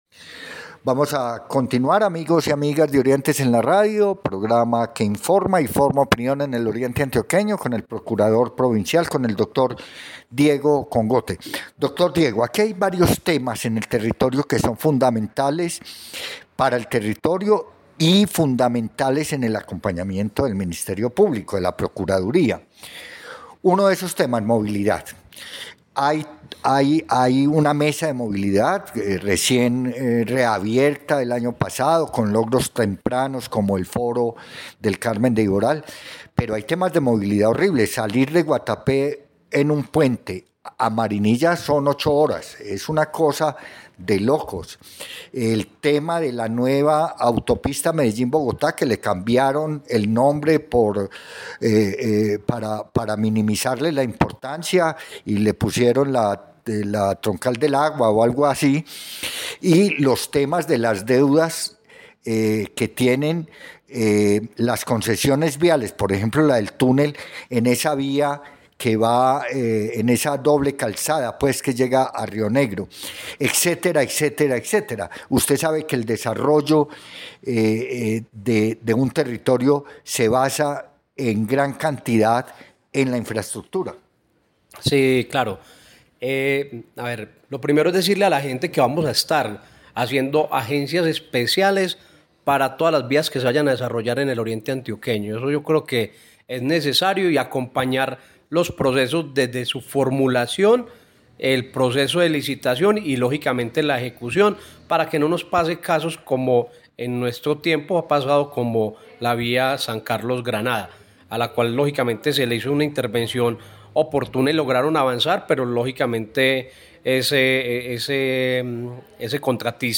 Entrevista con el procurador provincial:
Entrevista-con-el-procurador-parte-2.mp3